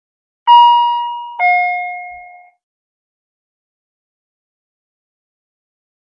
I.V. Pump Alert | Sneak On The Lot
I.V. Infusion Pump Alert; I.V. Infusion Pump Alert; Two-tone Warning Bell ( Sounds Like Car Seat belt Warning ), Close Perspective. Hospital.